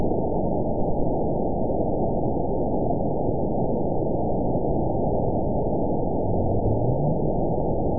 event 919794 date 01/23/24 time 22:54:21 GMT (1 year, 3 months ago) score 9.52 location TSS-AB02 detected by nrw target species NRW annotations +NRW Spectrogram: Frequency (kHz) vs. Time (s) audio not available .wav